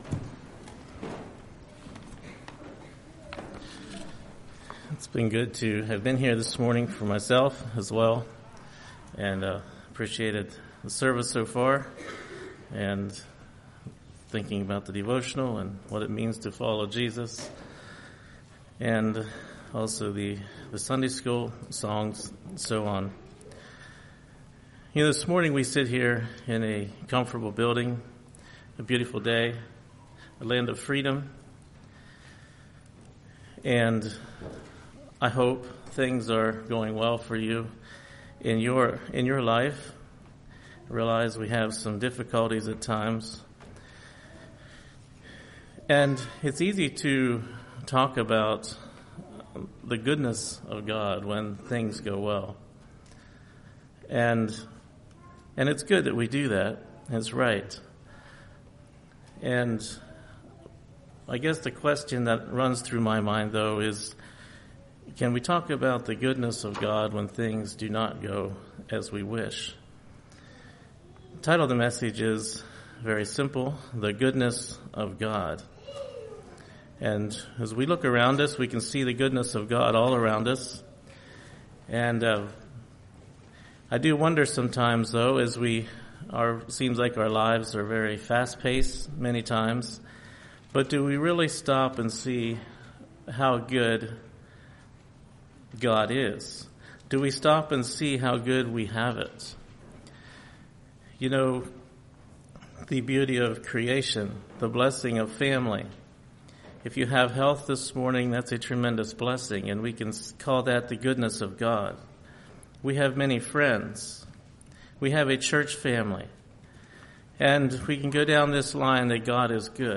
Sermons 02.25.23 Play Now Download to Device Psalm 1 Congregation